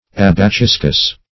Search Result for " abaciscus" : The Collaborative International Dictionary of English v.0.48: Abaciscus \Ab`a*cis"cus\ ([a^]b`[.a]*s[i^]s"k[u^]s), n. [Gr.